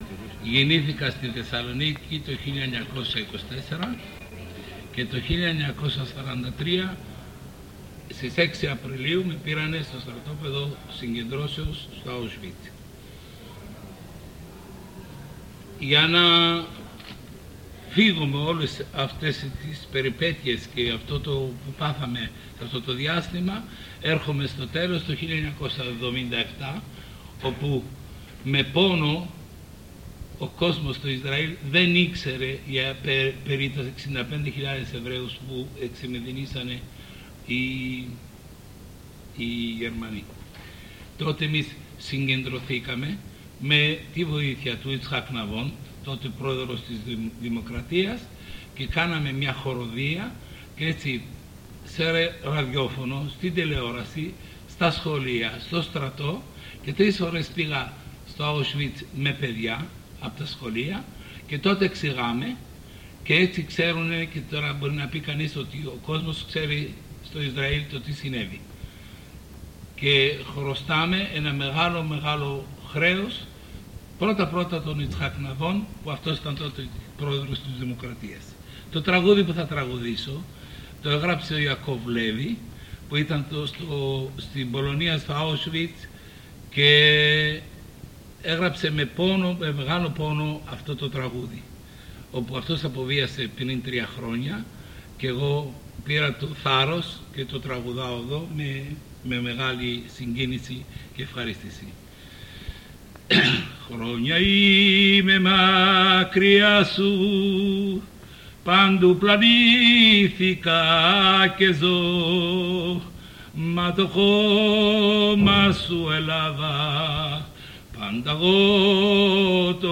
Δυο τραγούδια θεσσαλονικιών εβραίων - 100sources
Δύο τραγούδια θεσσαλονικών Εβραίων